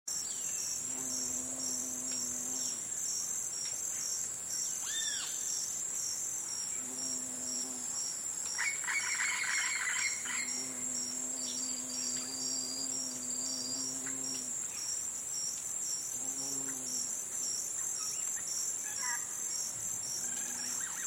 Здесь вы найдете успокаивающие композиции из шума прибоя, пения цикад и легкого ветра — идеальный фон для отдыха, работы или сна.
Шепот африканского заката в бескрайних саваннах